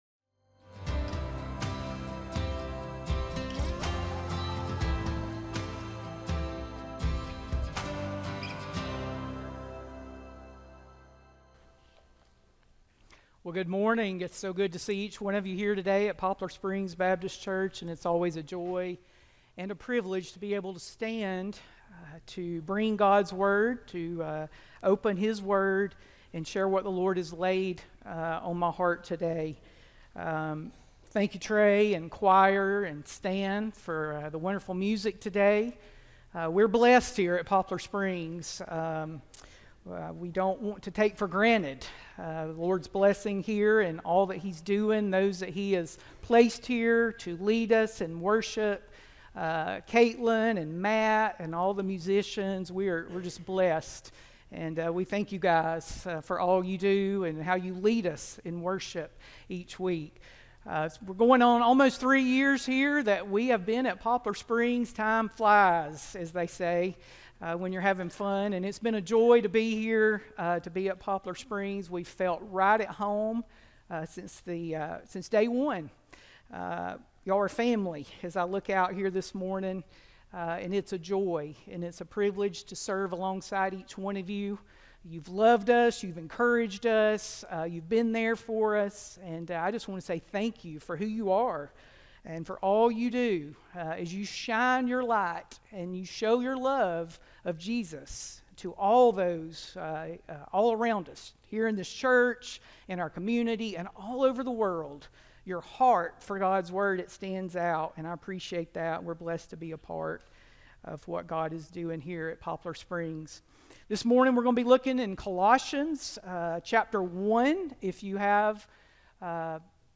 No Small Savior Sermons – Poplar Springs Baptist Church podcast